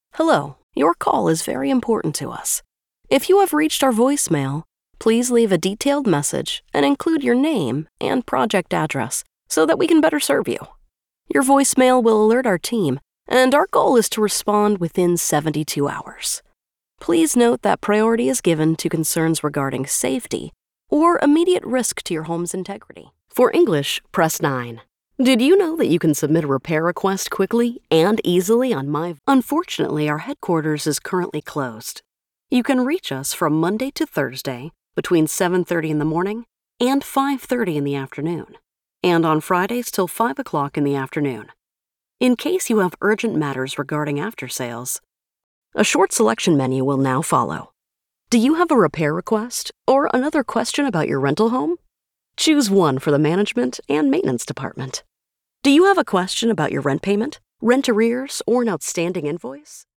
Inglés (Americano)
Comercial, Joven, Natural, Amable, Empresarial
Telefonía